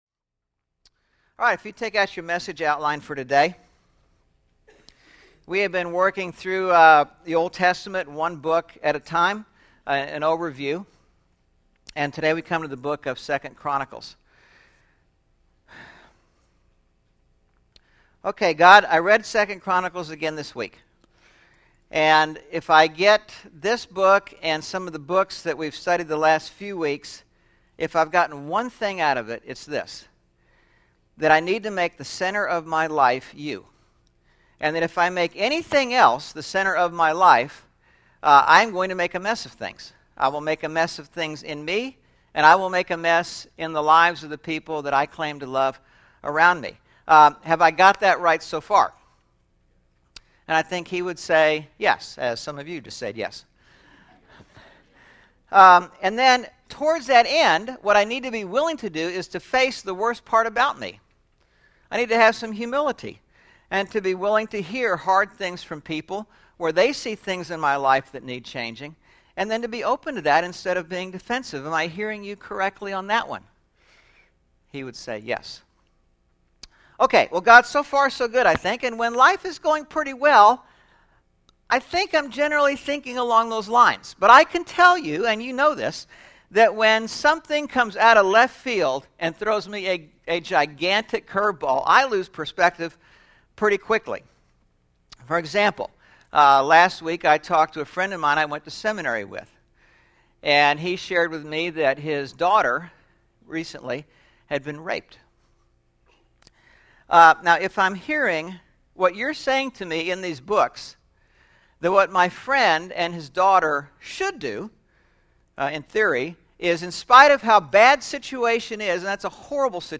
9/18/11 Sermon (The Book of 2 Chronicles) – Churches in Irvine, CA – Pacific Church of Irvine